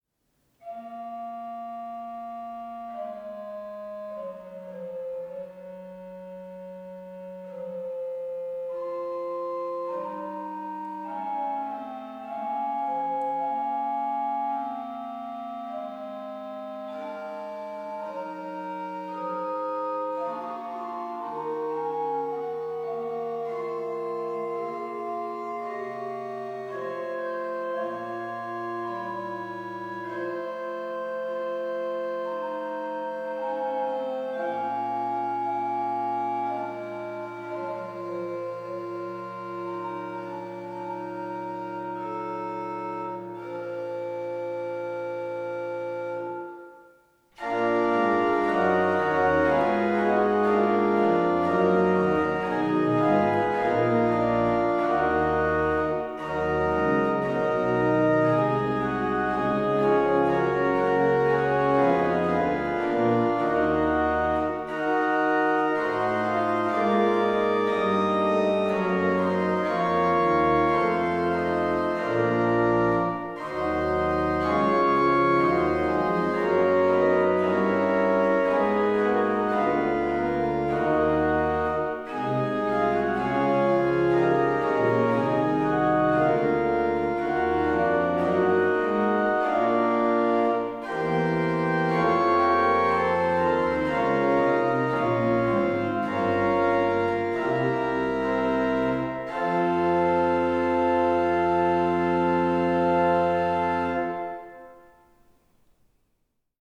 [manualiter]
[Choral]
Ped: Pr16, Oct8, Viol8